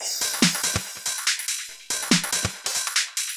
Index of /musicradar/uk-garage-samples/142bpm Lines n Loops/Beats
GA_BeatAFilter142-10.wav